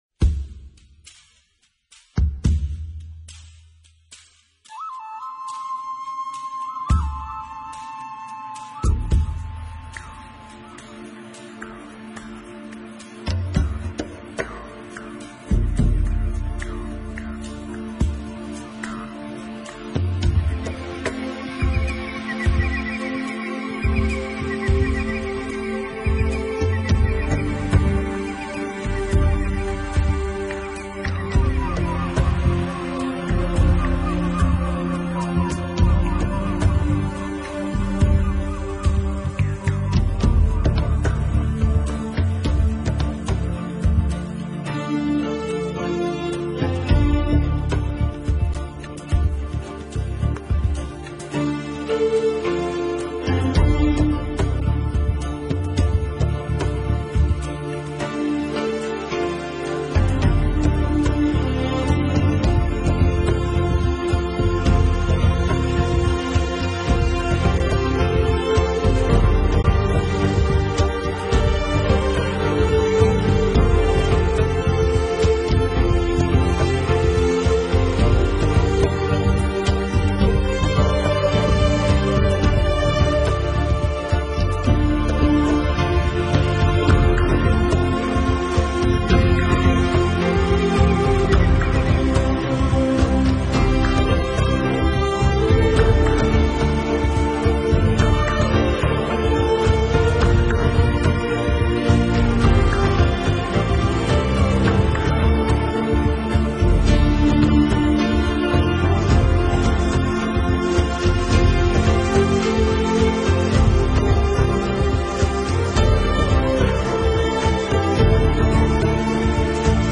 强劲深邃的鼓乐，气动山河的雄壮唱腔，直达天际的壮丽音场，您将犹如置
当多的鼓声作为整张专辑的主轴，同时加人声、笛声、鹰啸、狼号、雷声，出一场
……悠扬的哼唱声，时而是大自然的天籁之音，时而是雄壮高昂的旋律，这一切好